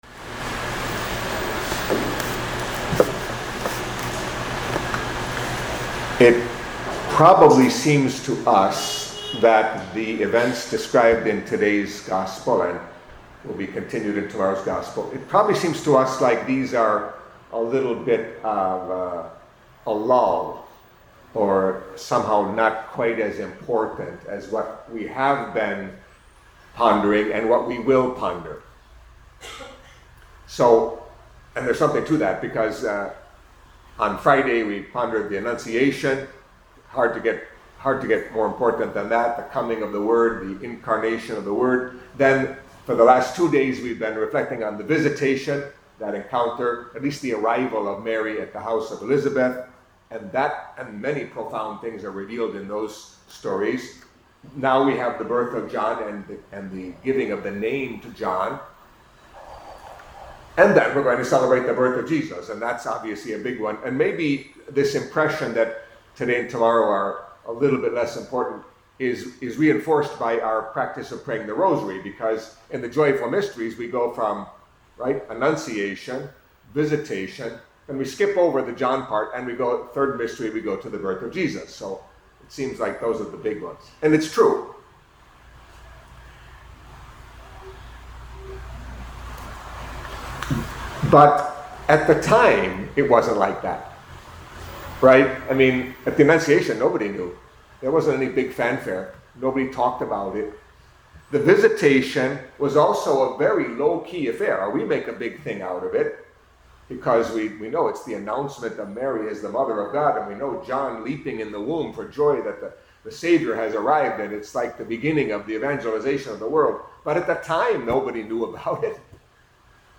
Catholic Mass homily for Monday of the Fourth Week of Advent